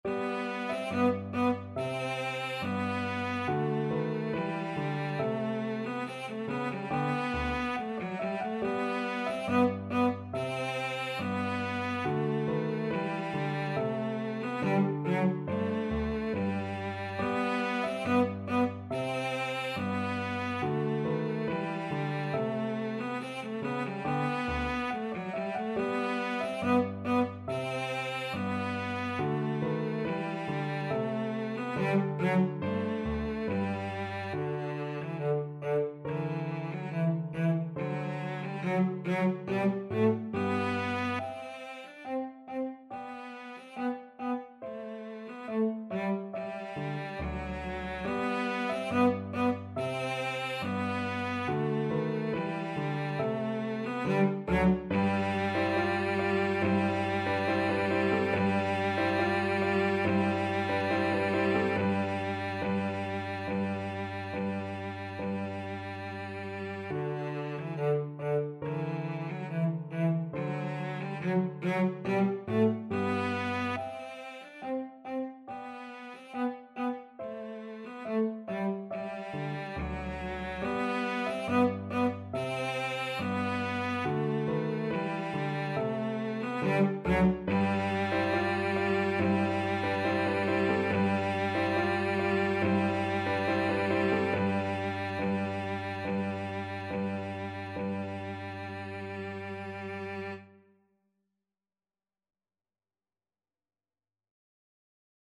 Cello
G major (Sounding Pitch) (View more G major Music for Cello )
2/4 (View more 2/4 Music)
Classical (View more Classical Cello Music)